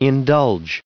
1940_indulge.ogg